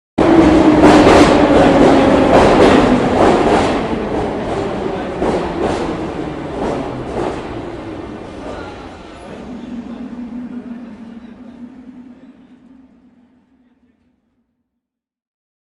Track Subway Arrive Ambiance
Track Subway Arrive Ambiance is a free ambient sound effect available for download in MP3 format.
Track Subway Arrive Ambiance.mp3